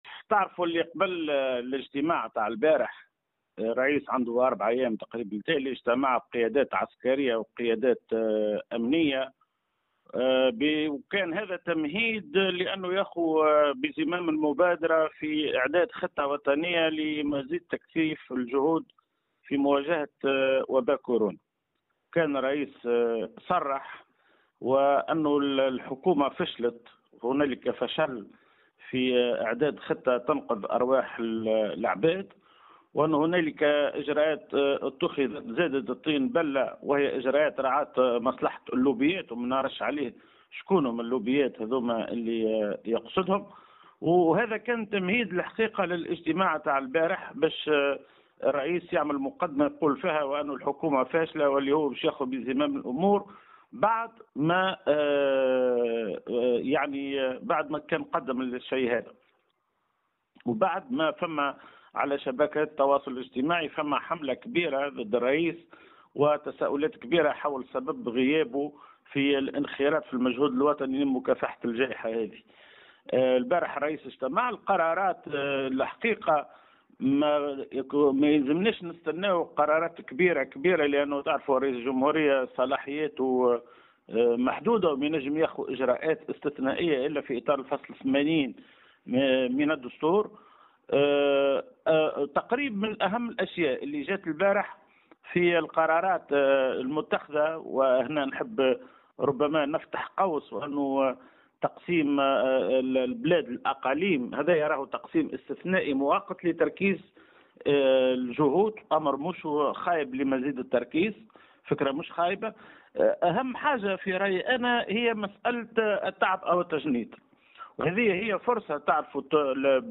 Dans une déclaration accordée à Tunisie numérique